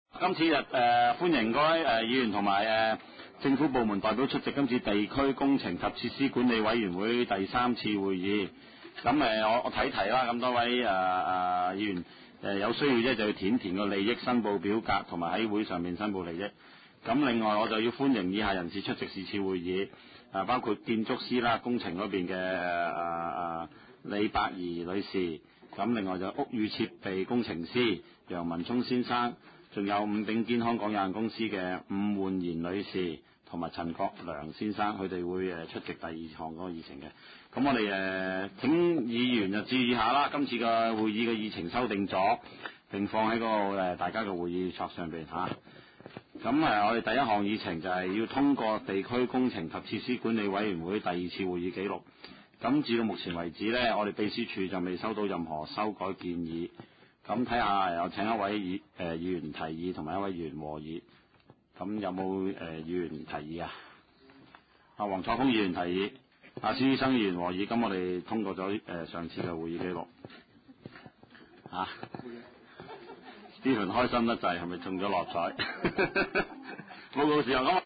地區工程及設施管理委員會第三次會議
灣仔民政事務處區議會會議室